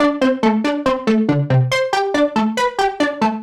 Index of /musicradar/french-house-chillout-samples/140bpm/Instruments
FHC_Arp A_140-A.wav